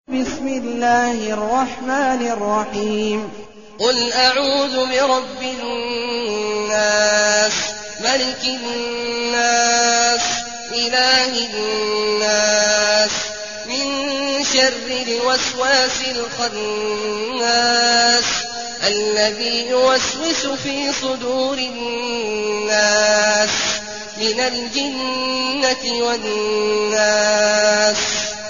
المكان: المسجد النبوي الشيخ: فضيلة الشيخ عبدالله الجهني فضيلة الشيخ عبدالله الجهني الناس